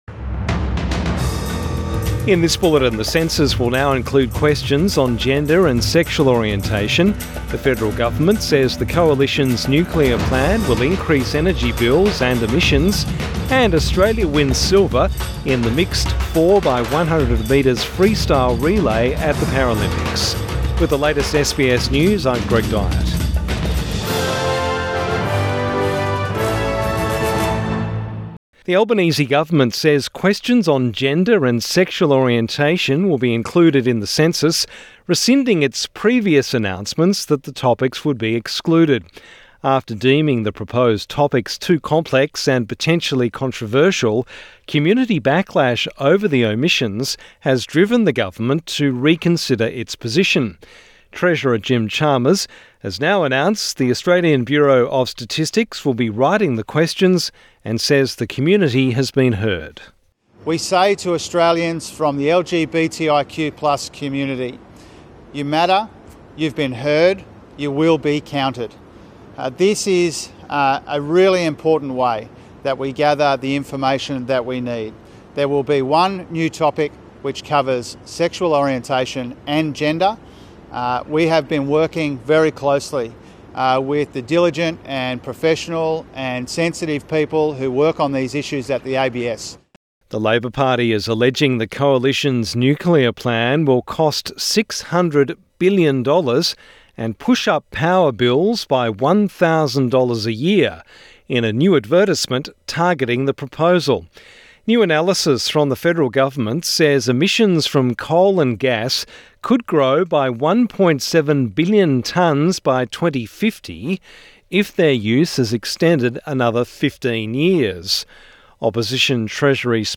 Evening News Bulletin 8 September 2024